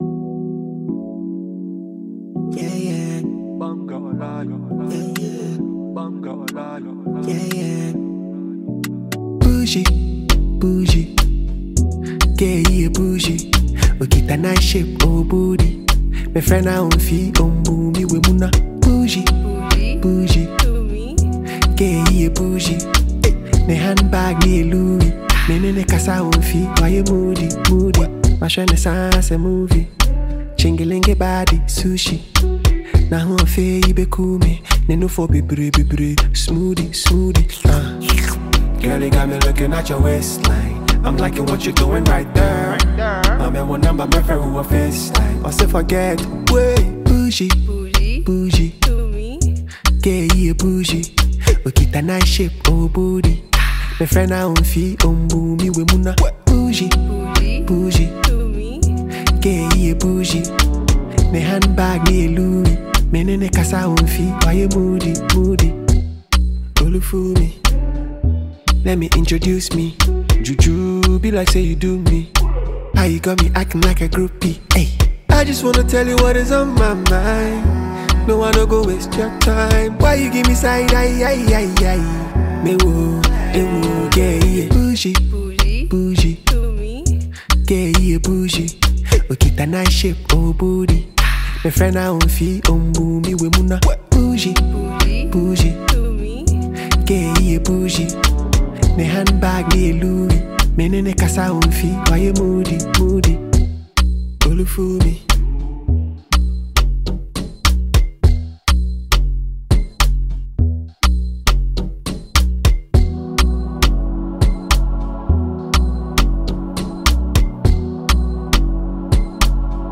With its modern production and playful lyrics